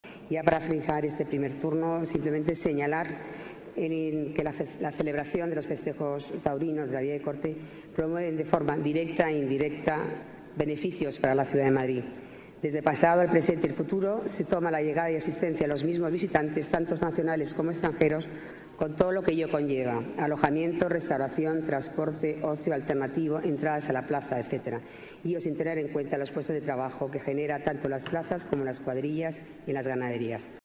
Nueva ventana:Declaraciones de Paloma García Romero, concejala de Tetuán y presidente de la Escuela Taurina Marcial Lalanda